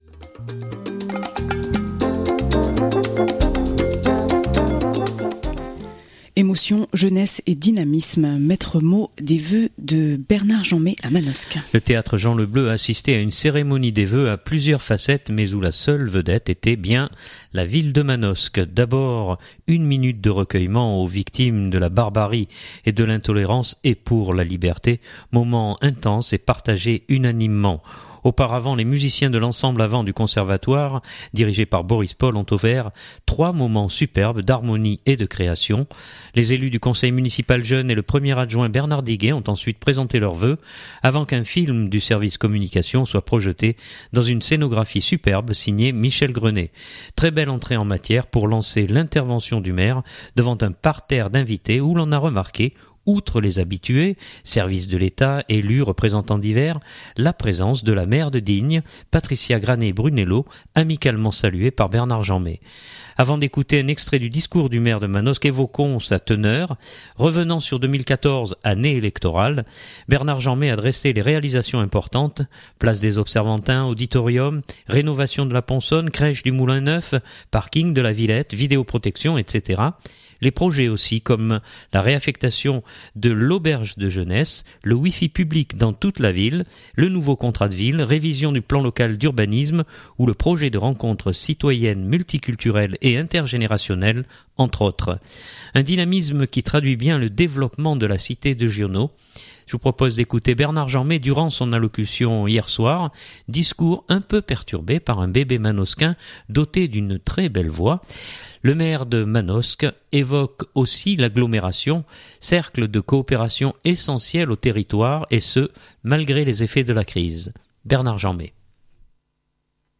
Le théâtre Jean Le Bleu a assisté à une cérémonie des vœux à plusieurs facettes mais où la seule vedette était bien la ville de Manosque.
Avant d’écouter un extrait du discours du Maire de Manosque, évoquons sa teneur.